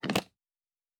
Fantasy Interface Sounds
UI Tight 06.wav